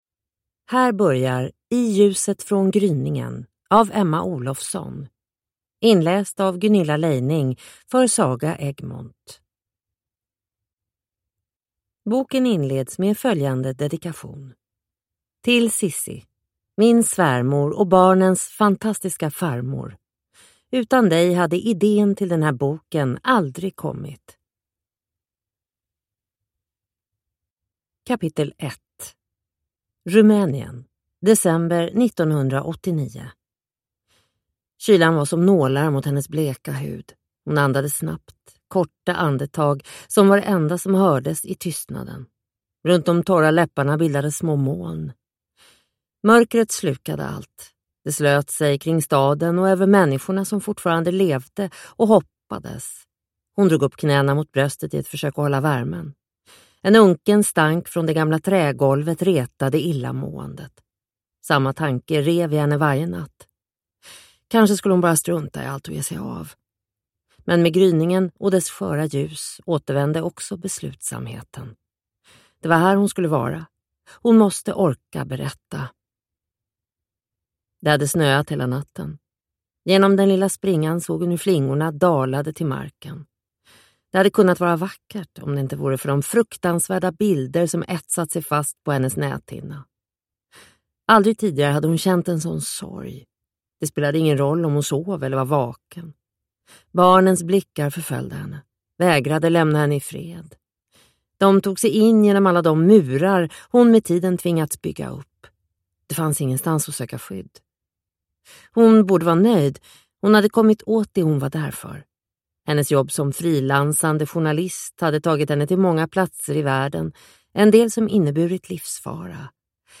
I ljuset från gryningen (ljudbok) av Emma Olofsson